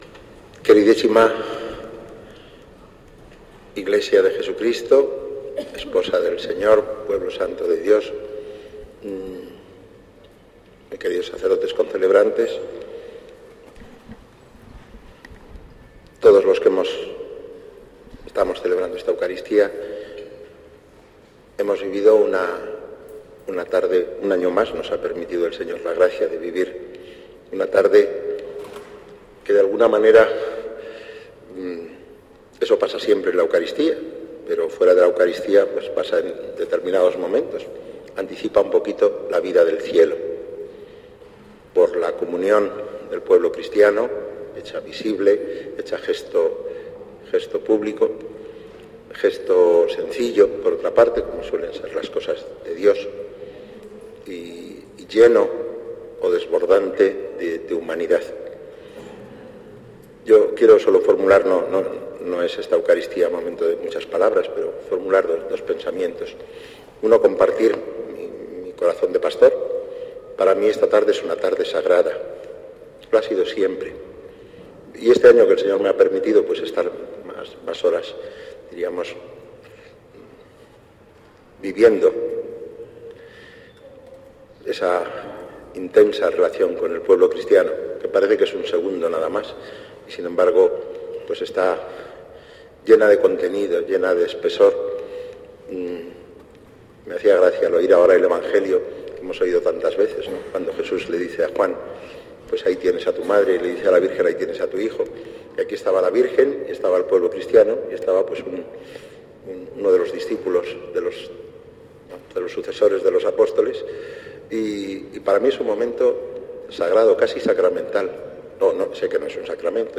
Homilia_Misa_Ofrenda_floral.mp3